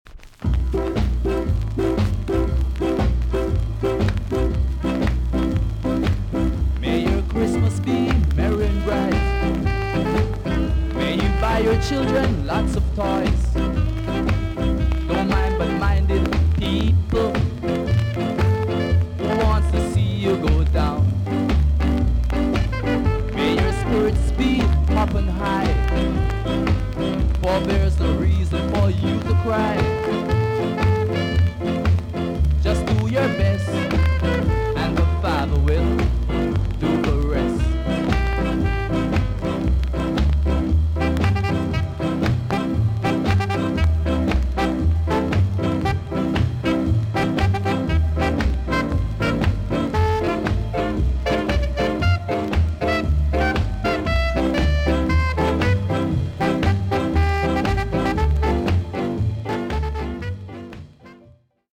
TOP >SKA & ROCKSTEADY
VG+~VG ok 全体的に軽いチリノイズが入ります。